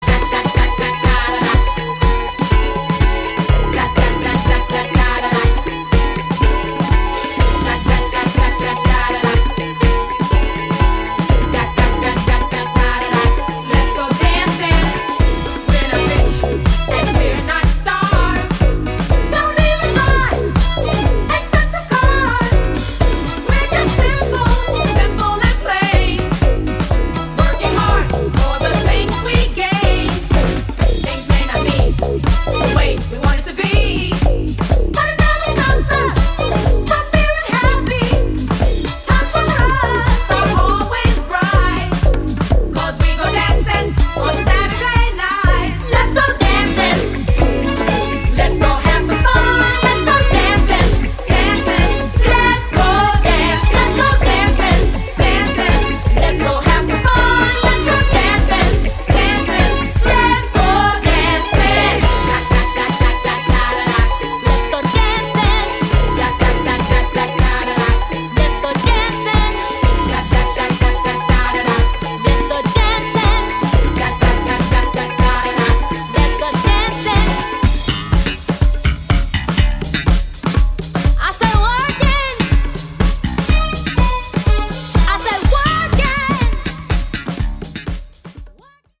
パーティ感溢れるさすがの一枚ですね！